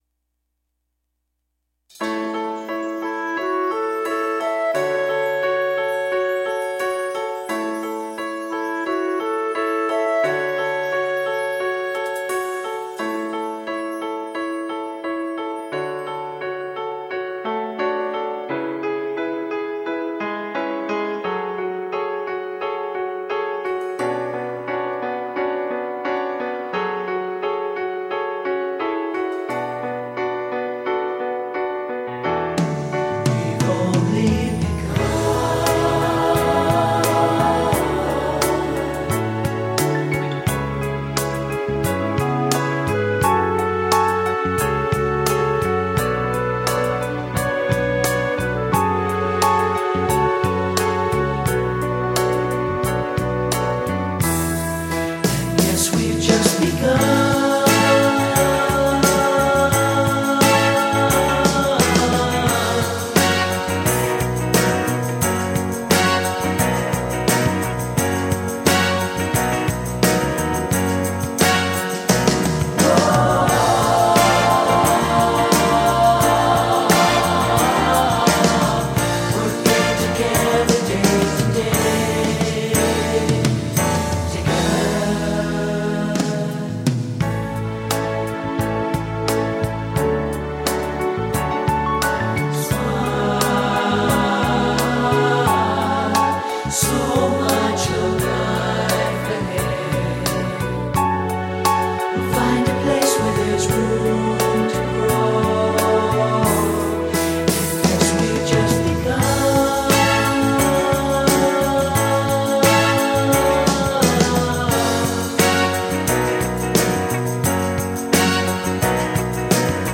rhythm track